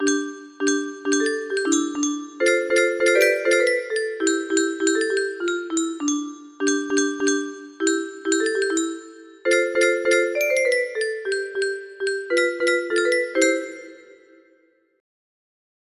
Oukraalliedjie 90 music box melody